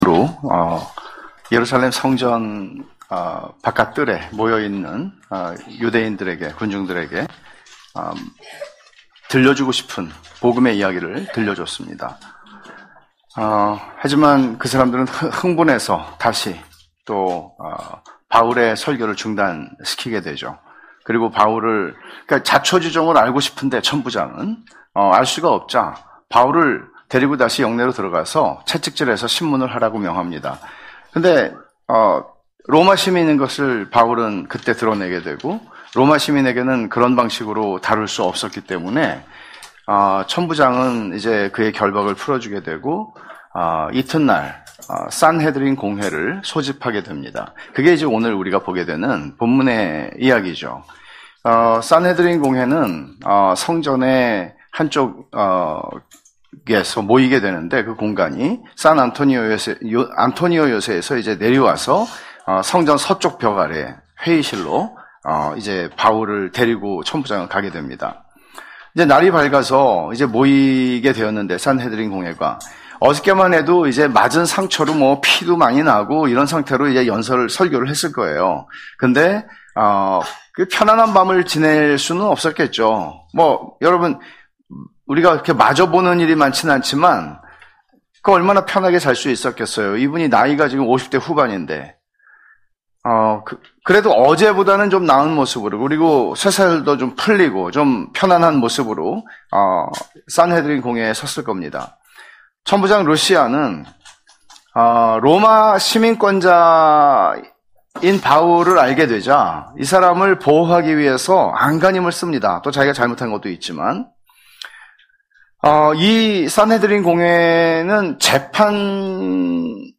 [사도행전 강해](42) 어디든지 예수 나를 이끌면(행 22:30-23:35)